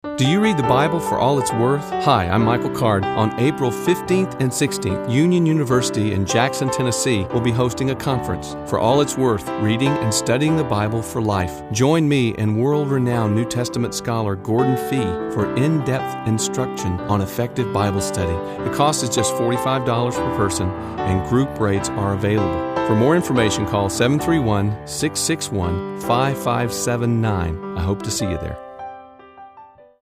Overview | Speakers | Schedule w/Audio | CD Order Form Listen to our Radio Ad featuring Michael Card Are you and those in your church reading the Bible for all it’s worth?
RadioSpot.mp3